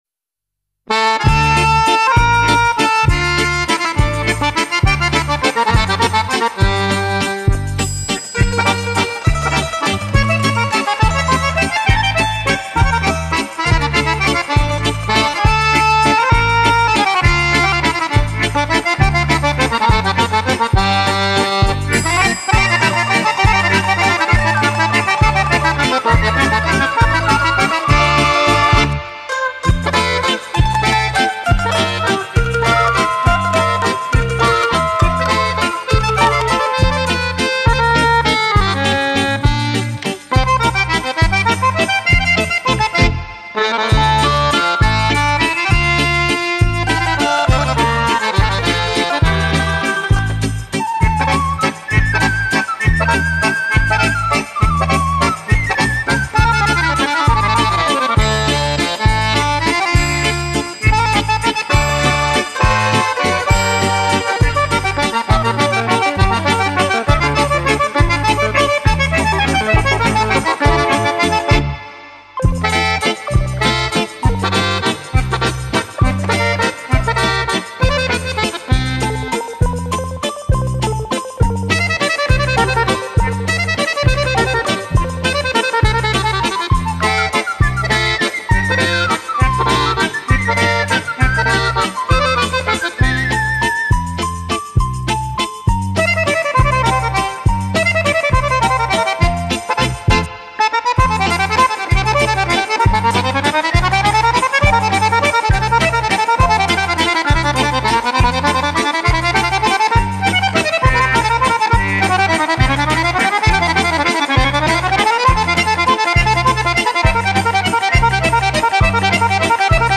Старый итальянский вальс